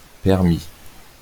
wymowa:
IPA/pɛʁ.mi/